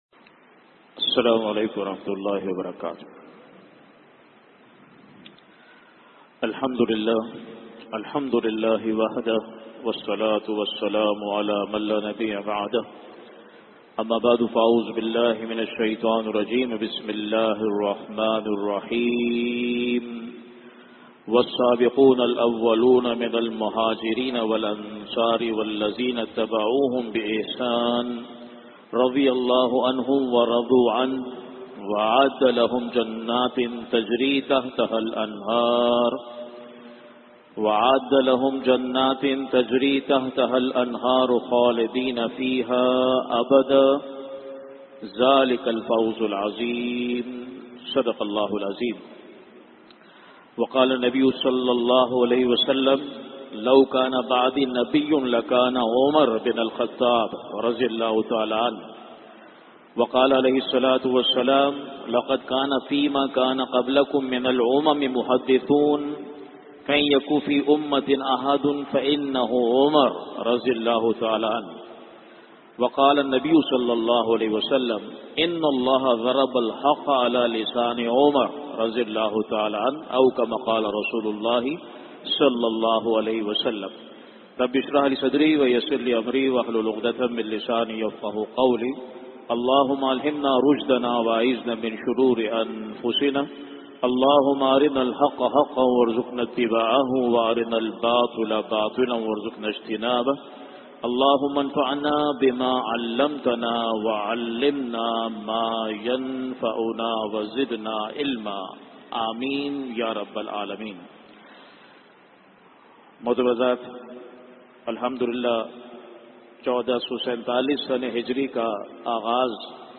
Khutbat-e-Jummah (Friday Sermons)
@ Masjid Jame-ul-Quran, Gulshan-e-Maymar The Virtuous Life and Martyrdom of Umar Farooq (RA)